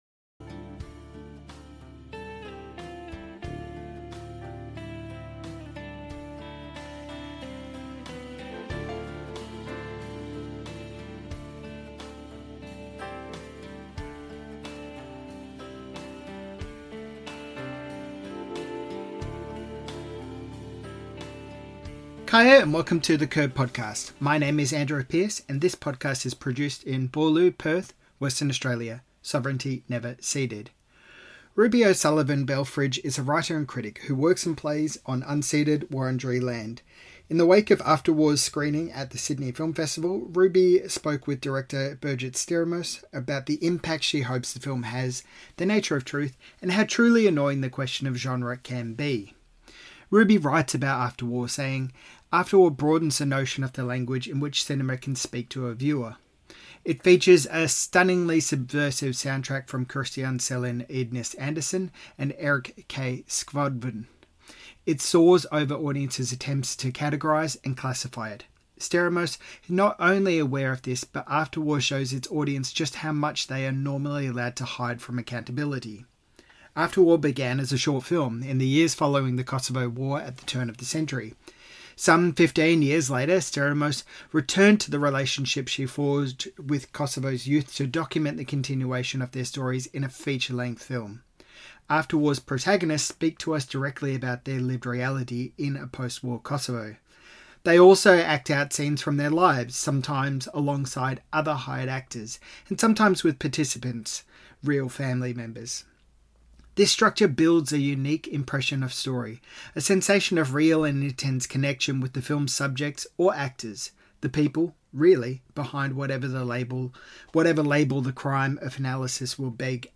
Interview - The Curb